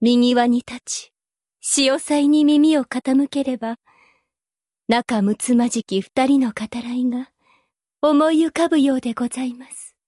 Voice Actor Satsuki Yukino
Voice Lines